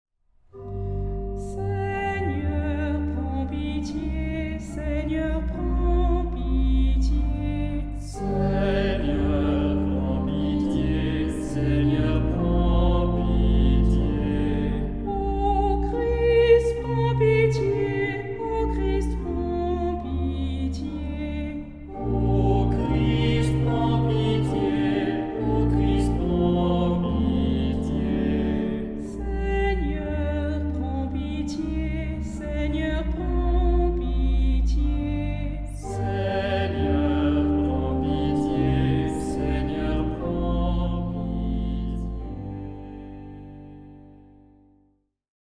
unison.
Messe. Sacré.
Tonalité : sol mineur